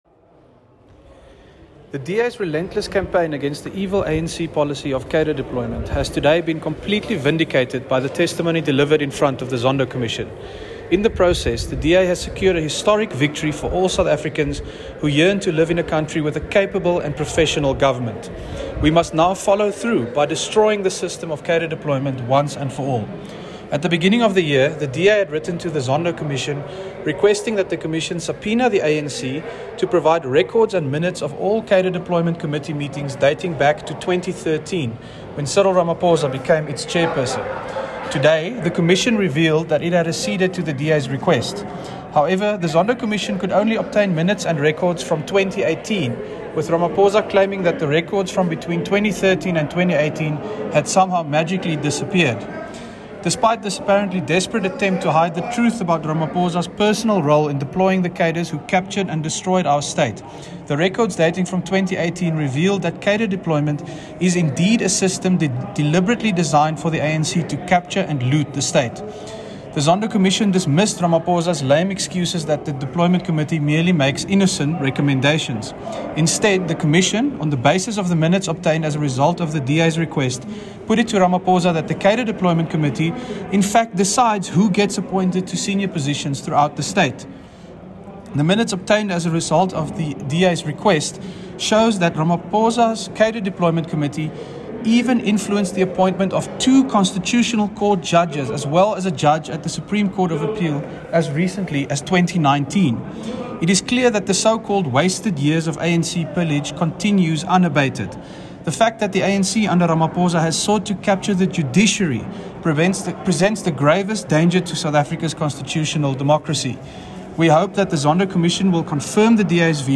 soundbite by Dr Leon Schreiber MP.